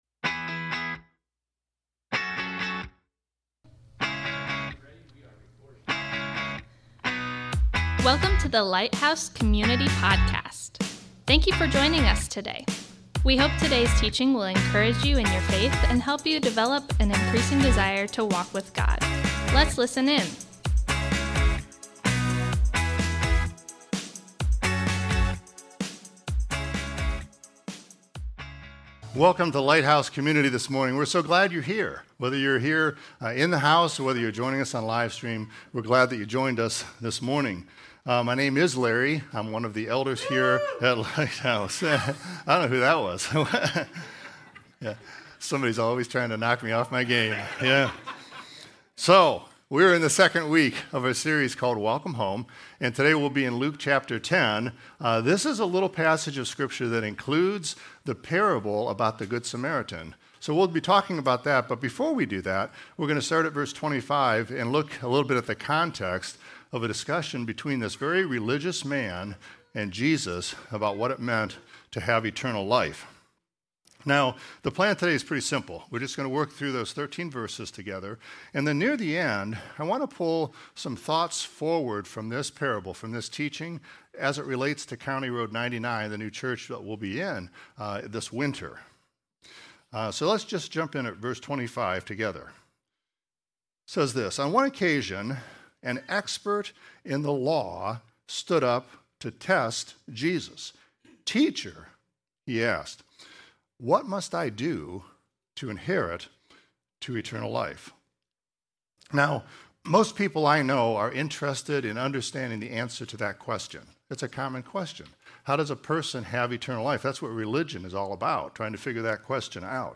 Thanks for joining us today as we worship together. We are continuing a new teaching series today called "Welcome Home". This is a unique topical series where we are providing a training on kindness. We will be looking at hospitality in all areas of ministry life.